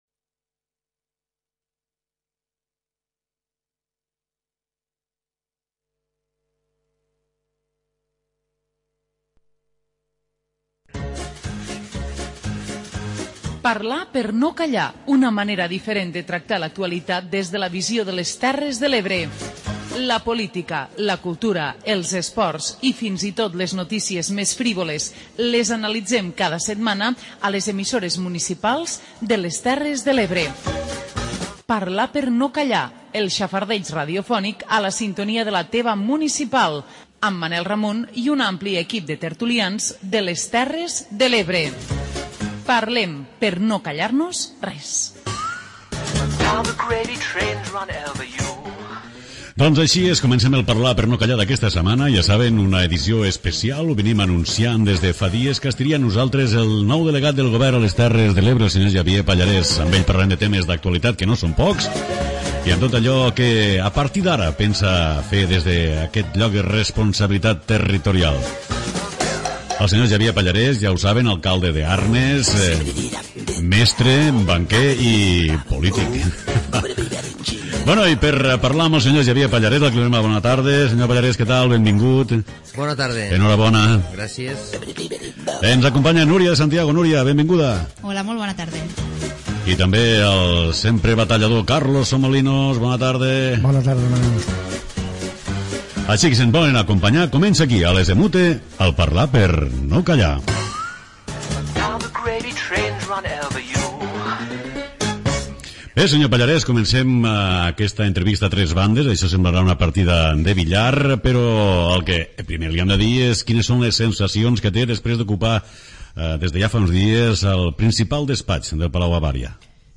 Parlar per no Callar, la tertúlia d'àmbit territorial d'EMUTE. 55 minuts de xerrada distesa amb gent que vol opinar. Busquem la foto, el titular i les carabasses de la setmana.